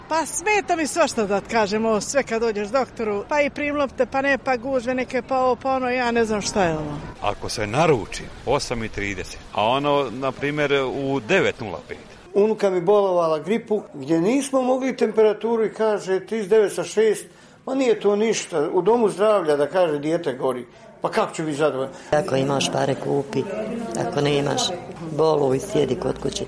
Banjalučani i Tuzlaci o zdravstvu u svojim gradovima